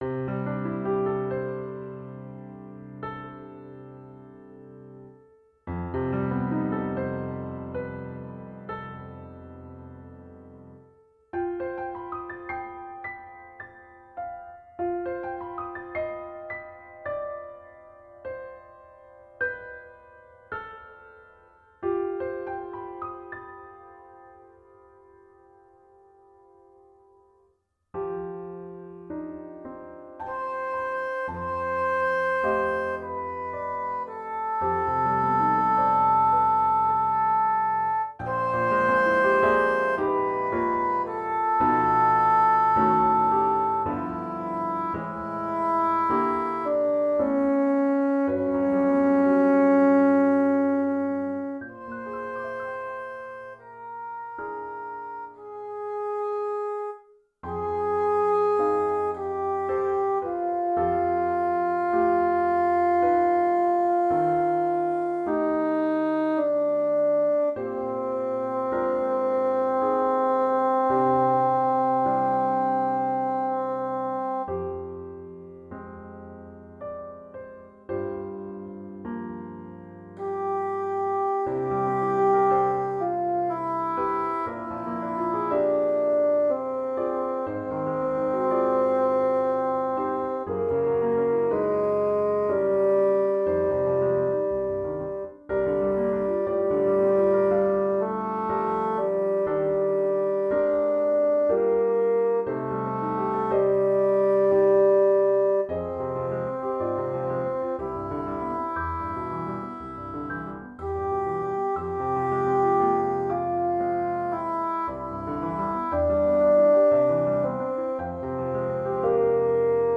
Bassoon , Music for Double Reeds